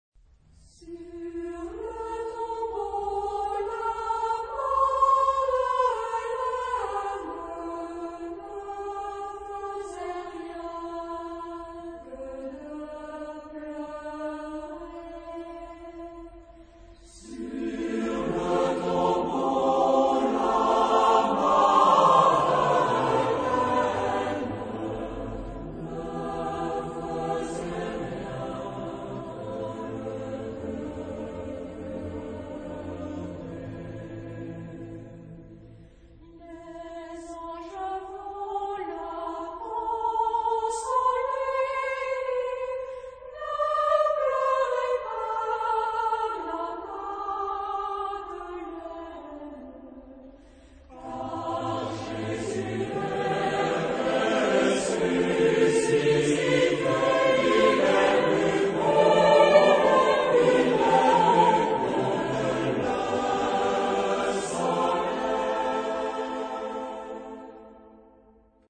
Charakter des Stückes: schmerzlich
Chorgattung: SATB  (4 gemischter Chor Stimmen )
Solisten: Alto (1) / Baryton (1)  (2 Solist(en))
Tonart(en): A äolisch